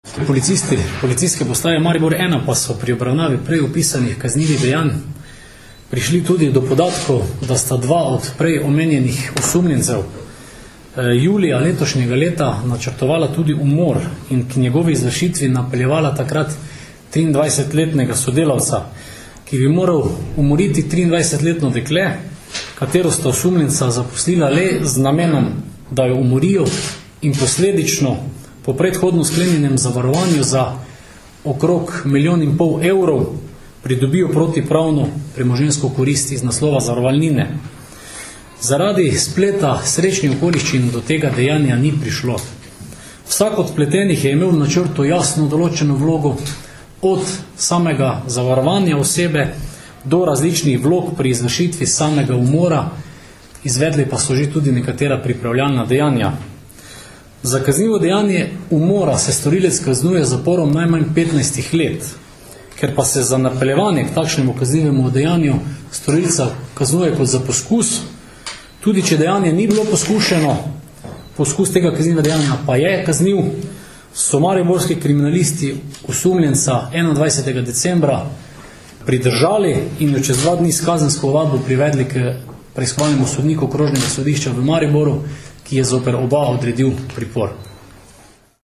Policija - Uspešno izsledili osumljence neupravičenega slikovnega snemanja in izsiljevanja ter poskusa umora - informacija z novinarske konference PU Maribor